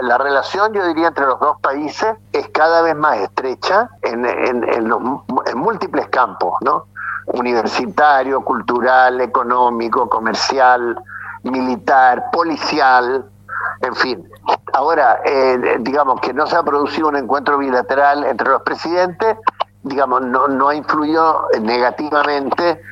En conversación con Radio Bío Bío, el exparlamentario se refirió a los intercambios comerciales que se están realizando con el país trasandino, lo que podría traer beneficios a Chile en el área agroalimentaria, de hidrocarburos, gas y petróleo.
viera-gallo-sumario.mp3